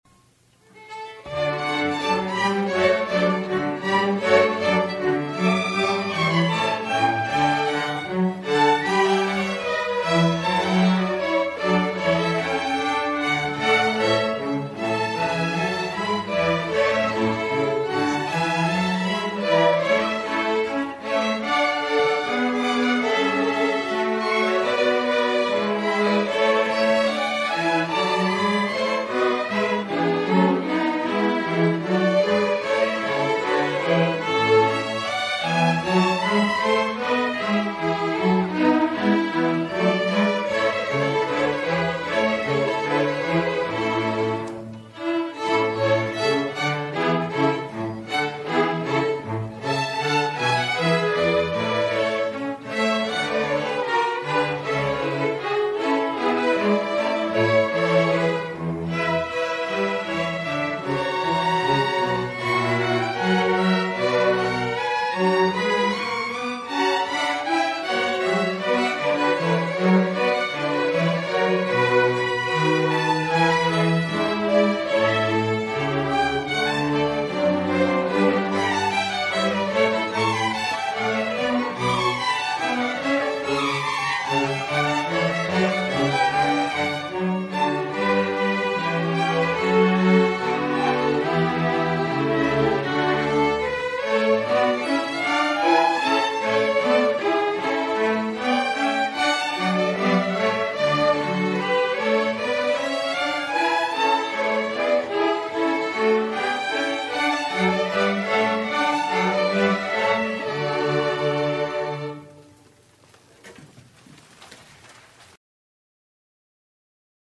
Queen City Community Orchestra
Fall 2013 Concert
Adagio